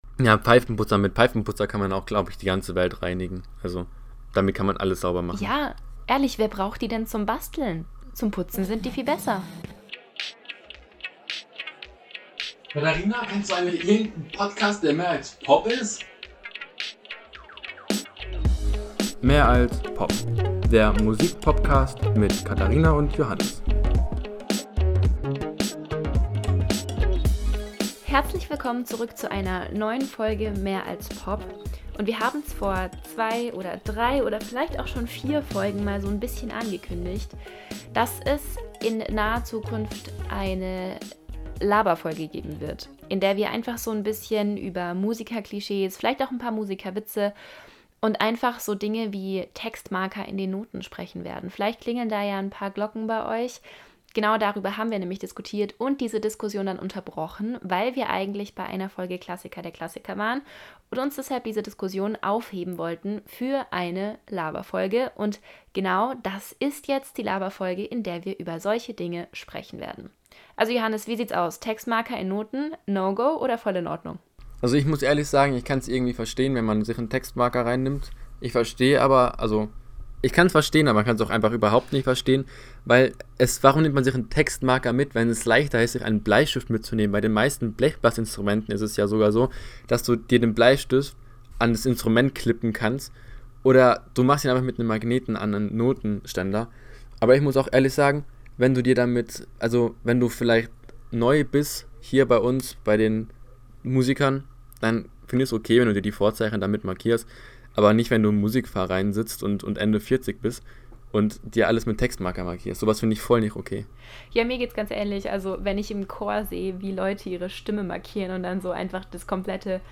Ohne viel Plan haben wir einfach mal aufgenommen, wie es ist, wenn wir so über Musik und Instrumente sprechen. Zwischen durch gibt es immer wieder Witze über diverse Instrumenten Gruppen - und natürlich auch über unsere eigenen Instrumente.